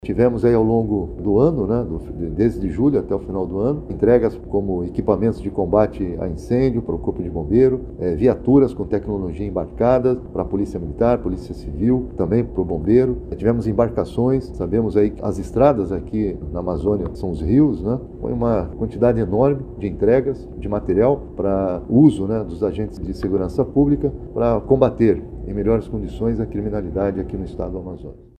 Sonora-general-Carlos-Alberto-Mansur-secretario-de-Seguranca-Publica-do-Amazonas.mp3